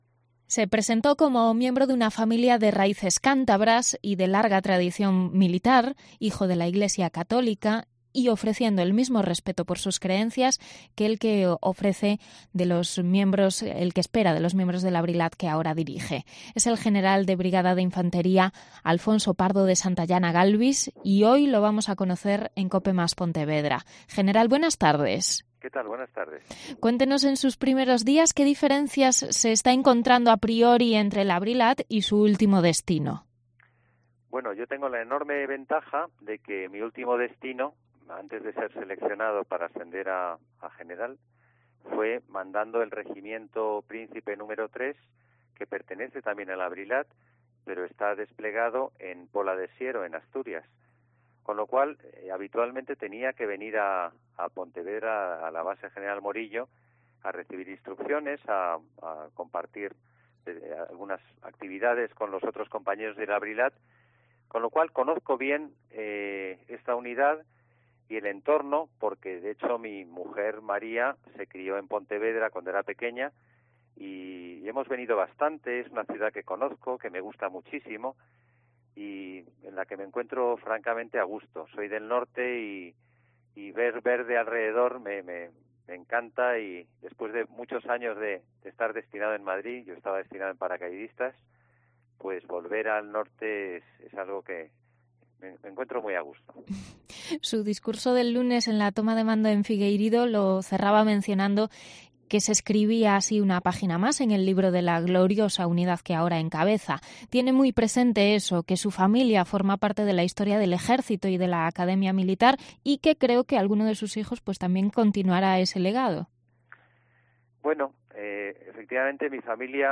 Entrevista al nuevo general de la Brilat, Alfonso Pardo de Santayana Galbis